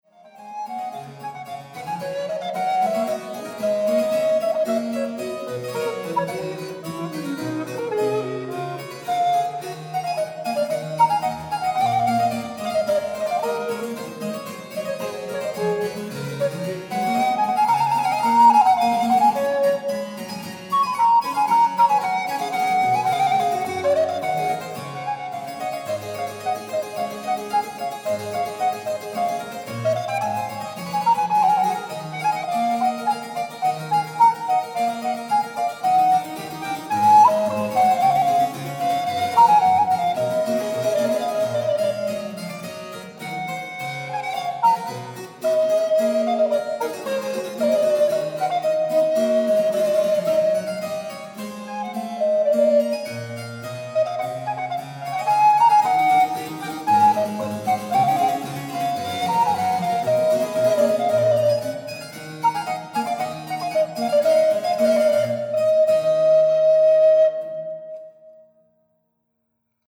Baroque